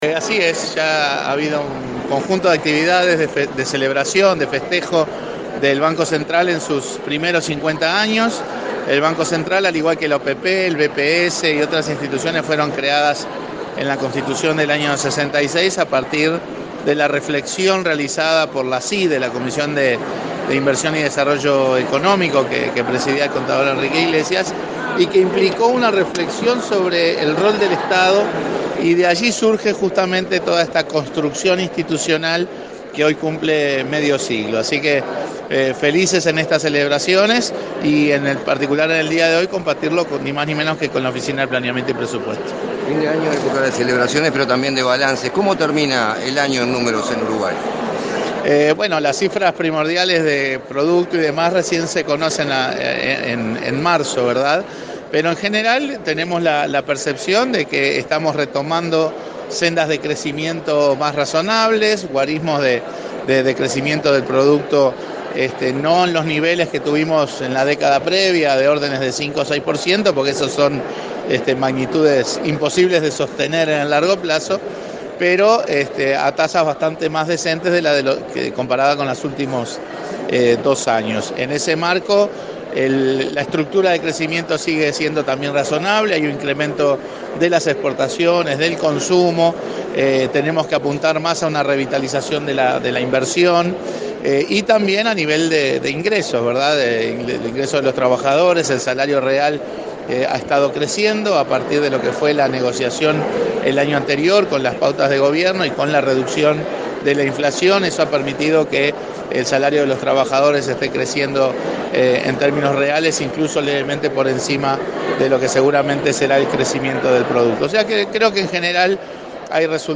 En el marco de los festejos de los 50 años del Banco Central, su presidente, Mario Bergara, sostuvo que el país está retomando sendas de crecimiento “más razonables”. Dijo a la prensa que la estructura de crecimiento también es razonable, con aumento de exportaciones y consumo. Los desafíos pasan por mantener la inflación dentro del rango objetivo, la estabilidad financiera y la competitividad de las empresas uruguayas.